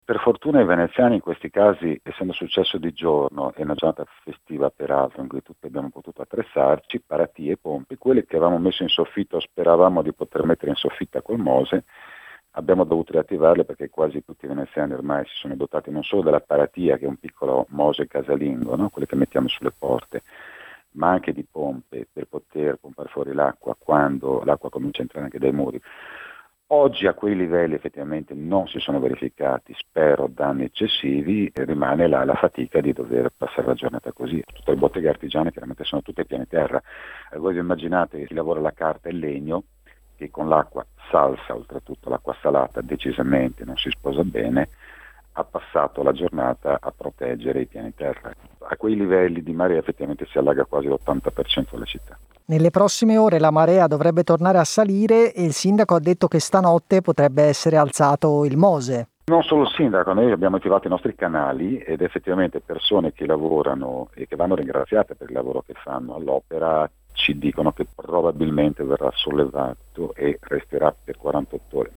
Il racconto della giornata di martedì 8 dicembre 2020 attraverso le notizie principali del giornale radio delle 19.30, dai dati dell’epidemia in Italia al voto di domani sulla riforma del Mes che fa tremare il governo.